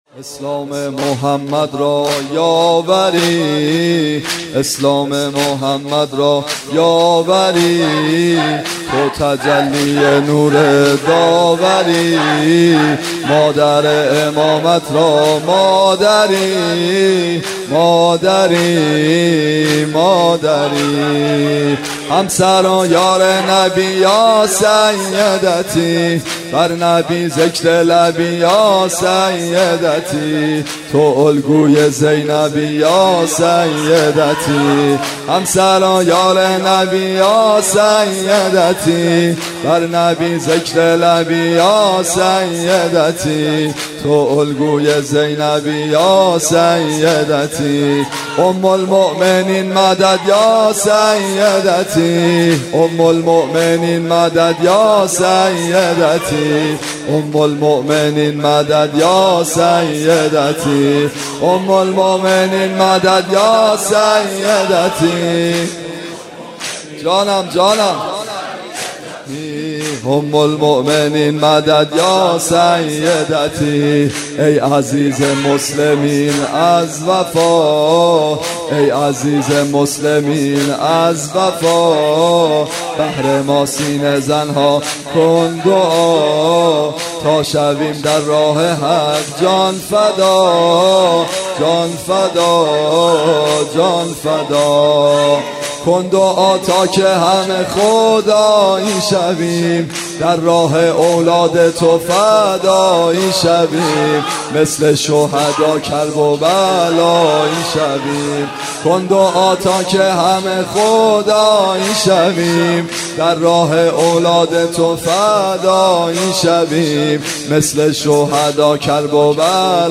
مرثیه و مداحی حضرت خديجه
1 اسلام محمد را یاوری  (جدید،سال93،هیئت یا مهدی عج اسلامشهر)